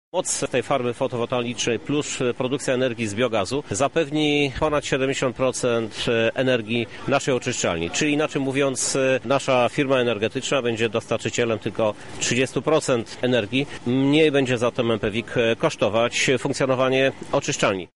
Powstanie farmy to działanie ekonomiczne i nastawione na przyszłość – przekonuje prezydent Lublina Krzysztof Żuk.